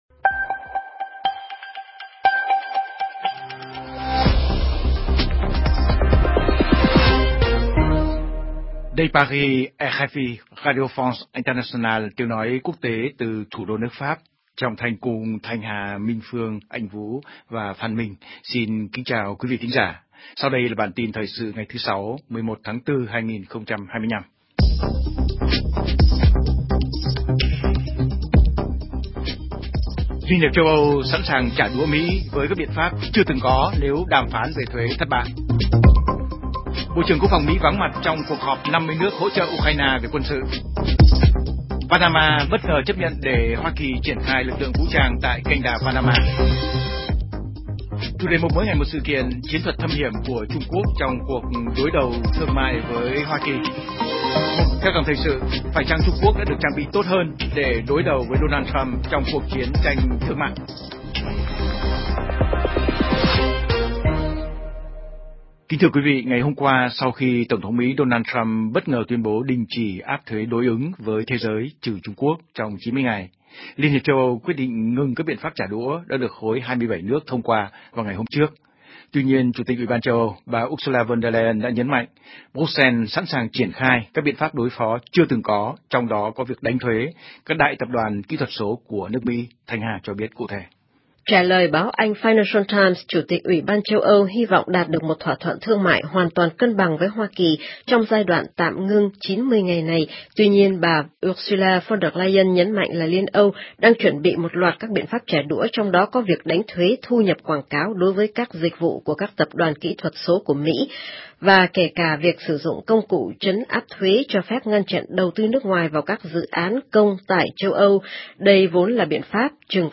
CHƯƠNG TRÌNH PHÁT THANH 60 PHÚT Xem tin trên website RFI Tiếng Việt Hoặc bấm vào đây để xem qua Facebook